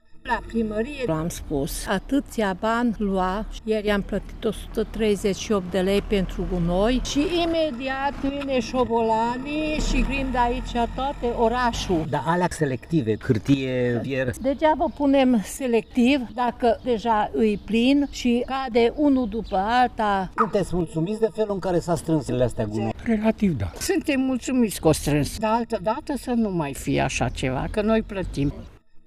În ce privește curățenia, după zilele în care orașul arăta deplorabil ca aspect și siguranță sanitară, acum majoritatea mureșenilor se declară mulțumiți că s-a reușit totuși strângerea gunoaielor. Ei remarcă însă o problemă nerezolvată, care se referă la deșeurile selective, unde containerele specializate au volum mic, se umplu repede și mereu sunt pline: